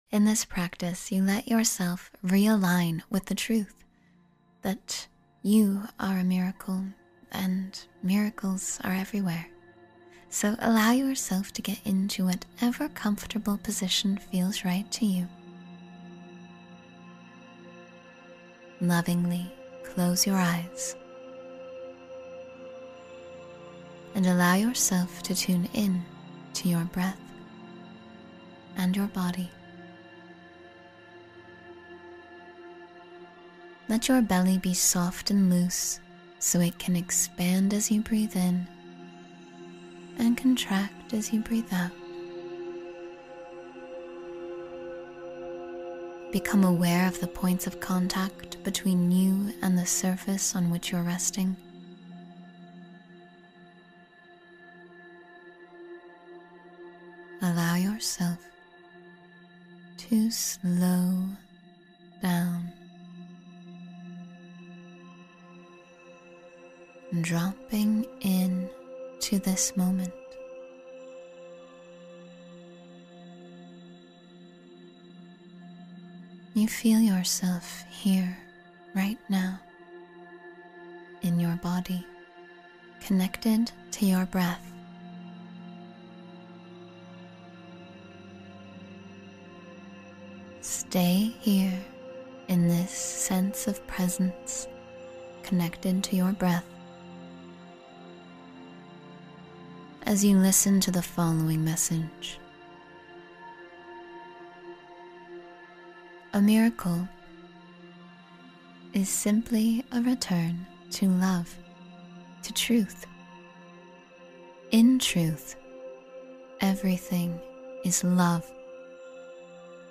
You’ll learn how to build resilience, nurture inner peace, and support your mental health through the gentle rhythm of guided breathing, visualization, and stillness.